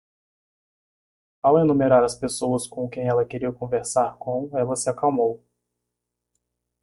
Pronounced as (IPA)
/e.nu.meˈɾa(ʁ)/